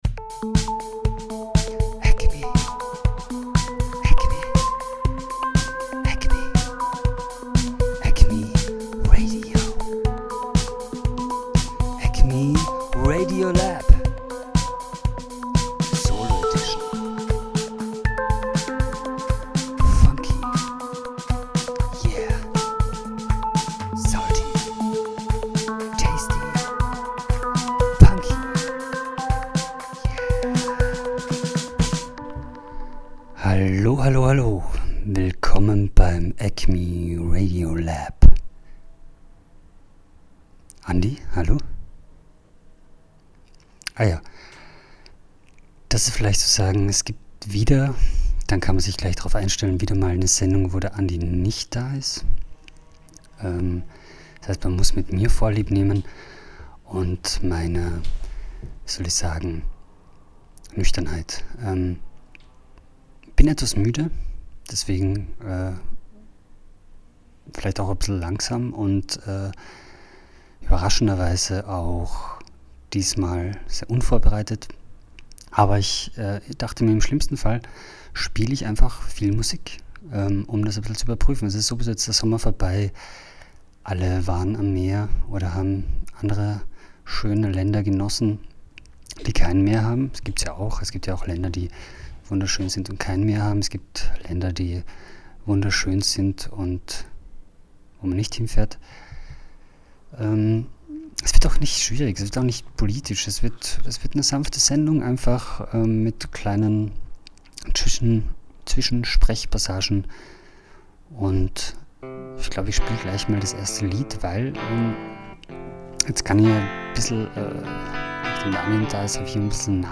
Punksongs
schwelgerisch und anarchisch.